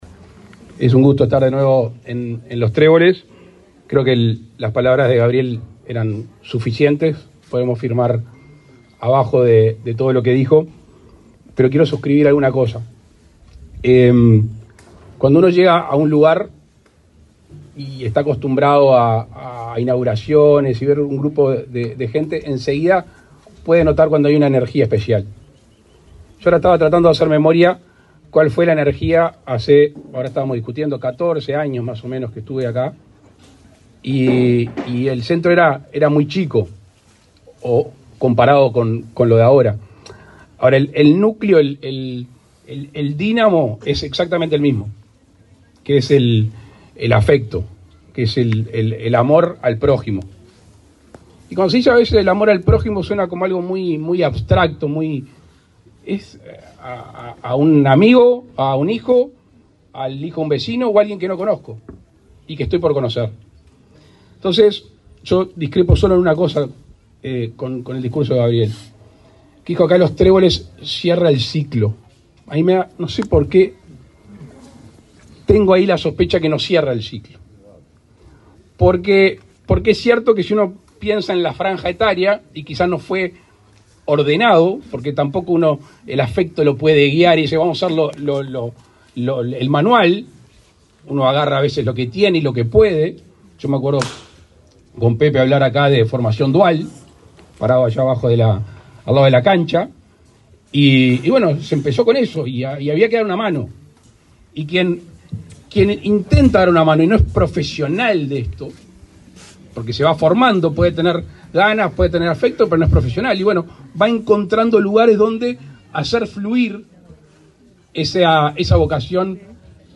Palabras del presidente Luis Lacalle Pou
Este viernes 23, el presidente de la República, Luis Lacalle Pou, encabezó el acto de inauguración del centro de atención a la infancia y la familia